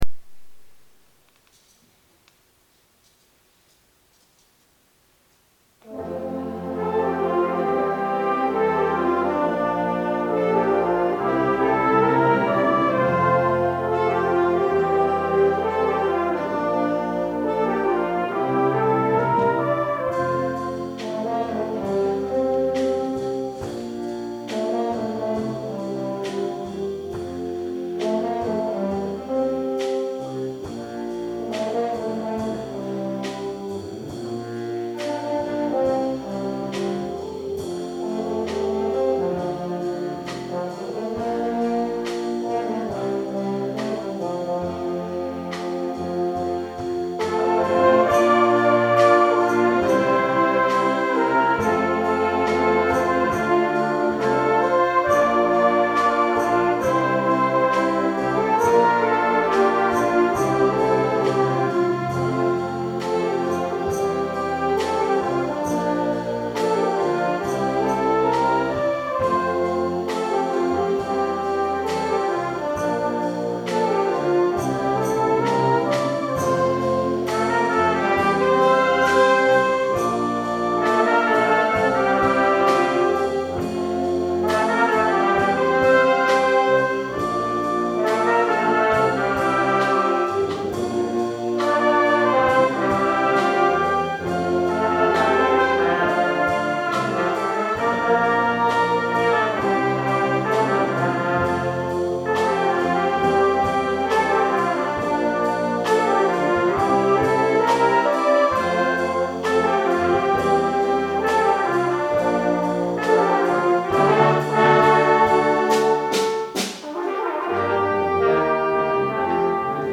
Aufnahme Konzertmusikbewertung 2013